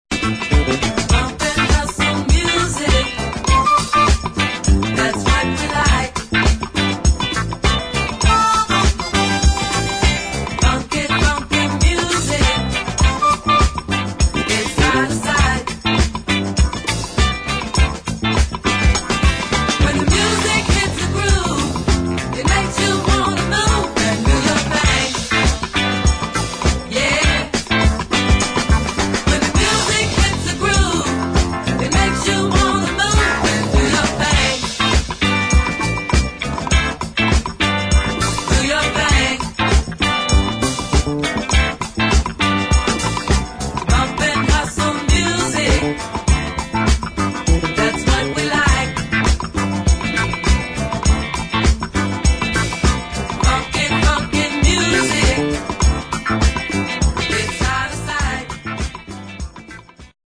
[ JAZZ / FUNK / SOUL ]